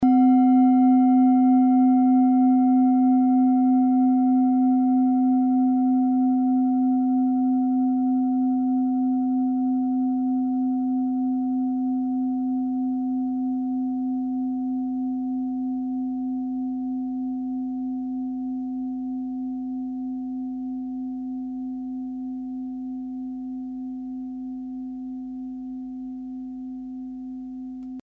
Klangschalen-Typ: Bengalen
Klangschale Nr.6
(Aufgenommen mit dem Filzklöppel/Gummischlegel)
klangschale-set-2-6.mp3